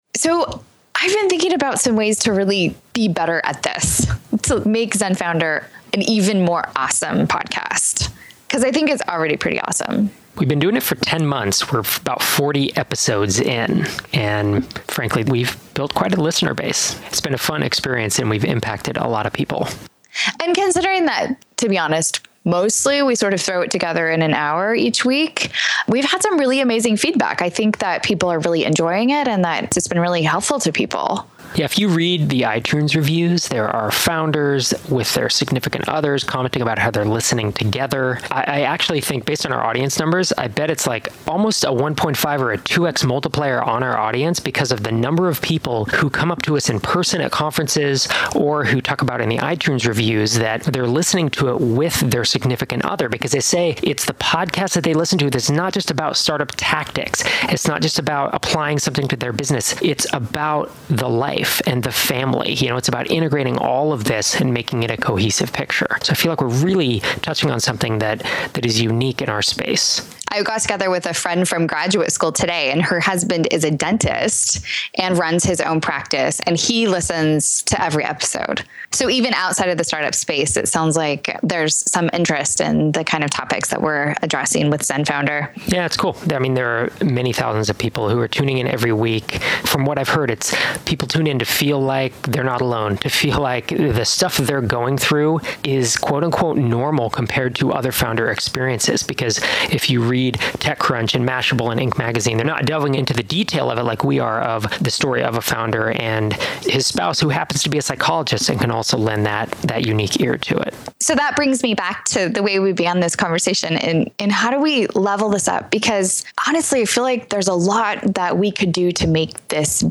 Episode 42: I Guarantee It: An Interview with George Zimmer